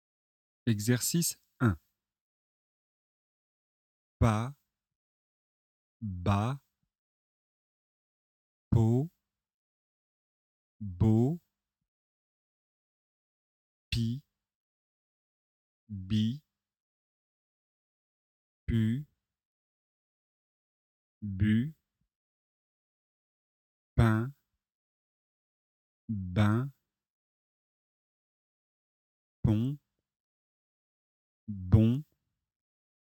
Leçon de phonétique et de prononciation, niveau débutant (A1).
• [b] (voisée : les cordes vocales vibrent)
Écoutez et répétez.